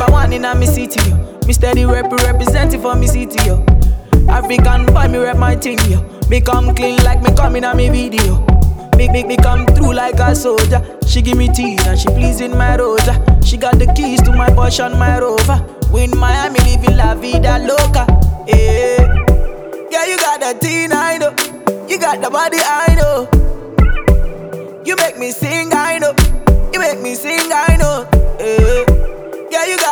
• Hip-Hop/Rap